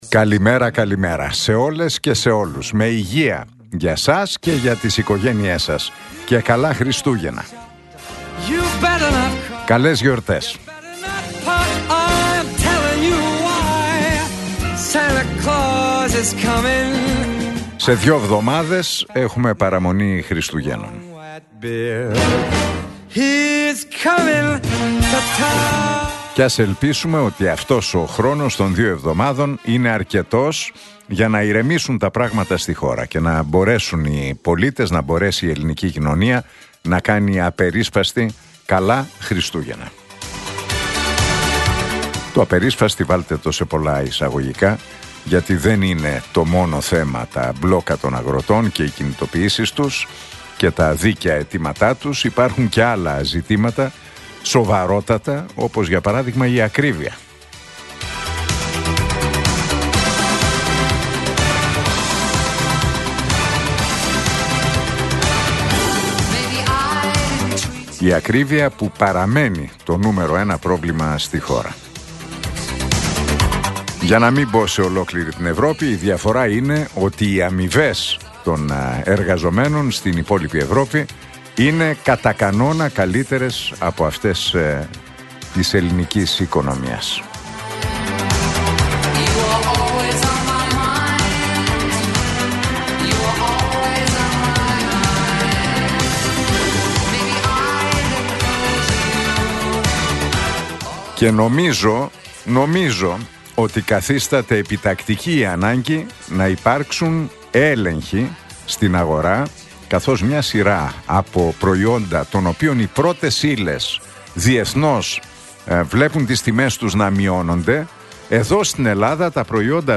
Ακούστε το σχόλιο του Νίκου Χατζηνικολάου στον ραδιοφωνικό σταθμό Realfm 97,8, την Τετάρτη 10 Δεκεμβρίου 2025.